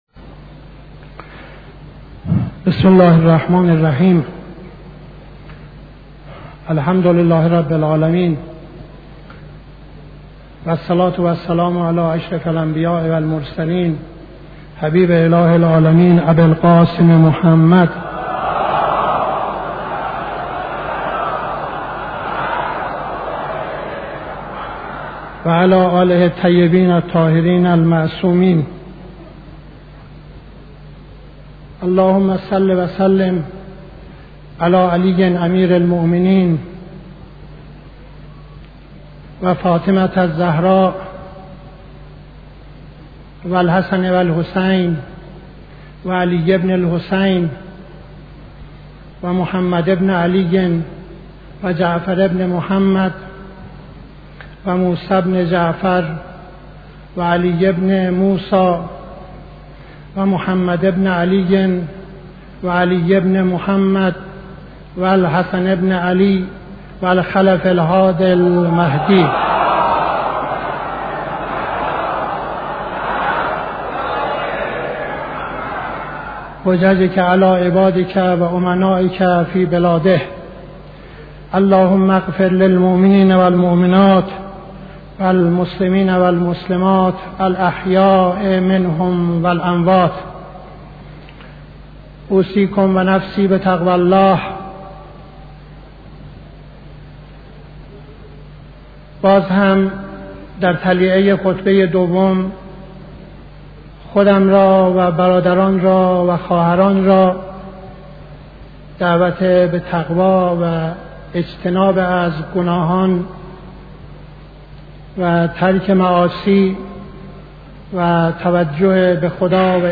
خطبه دوم نماز جمعه 12-04-71